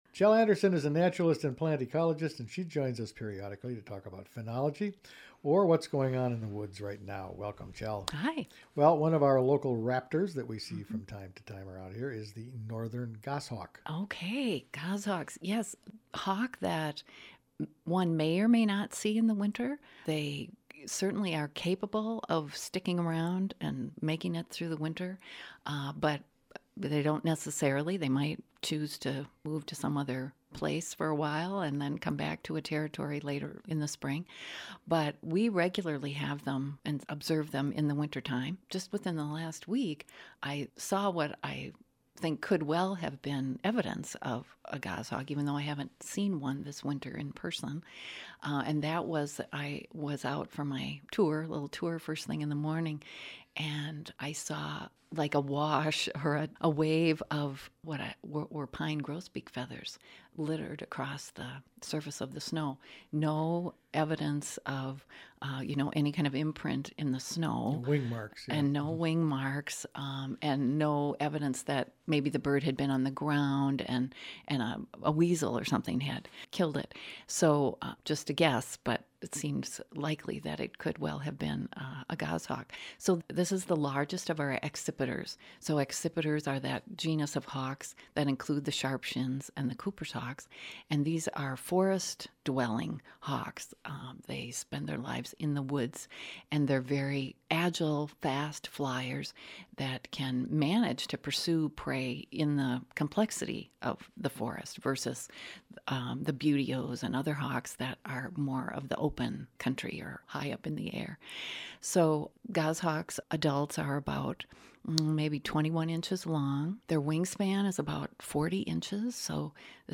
talks with naturalist